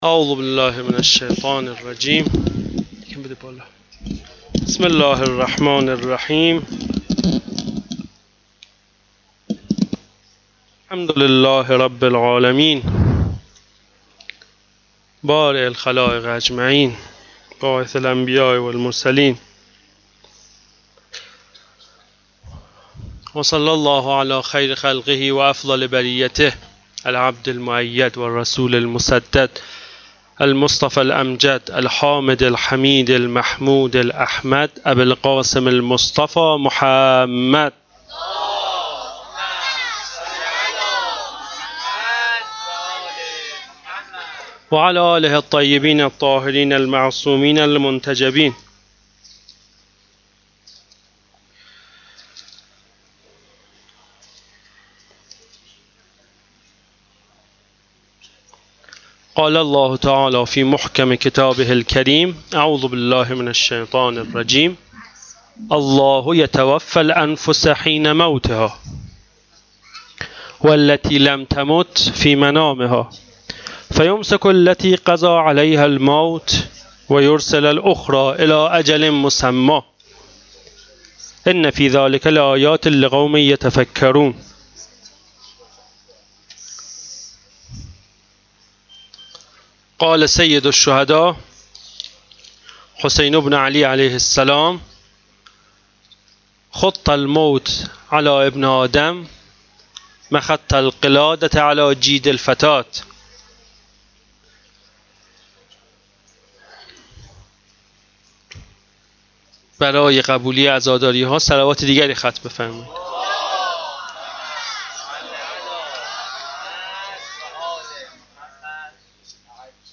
سخنرانی شب هشتم محرم93
Sokhanrani-Shabe-08-moharram93.mp3